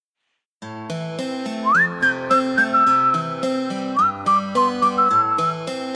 That One Josh Hutcherson Whistle Edit